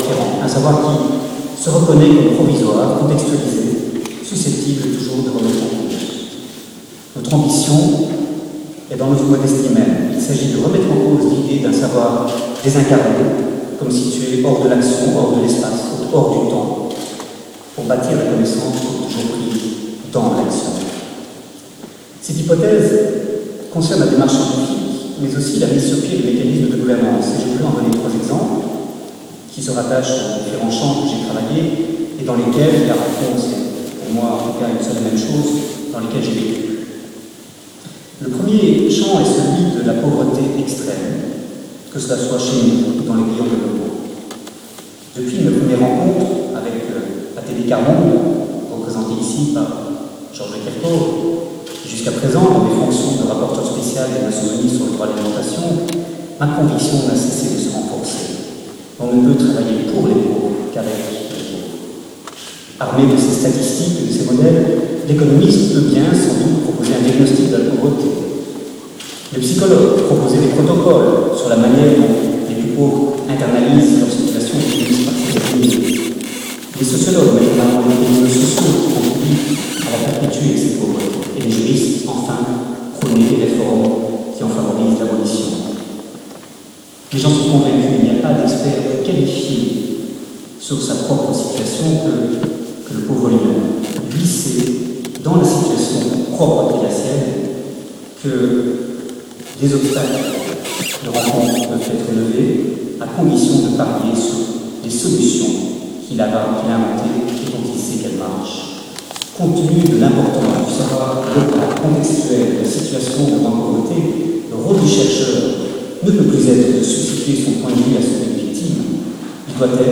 Discours d’Olivier De Schutter, Prix Francqui – extrait
Lors de son discours à l’Académie lors de la réception du Prix Francqui, Olivier De Schutter parle de pauvreté et d’agro-écologie.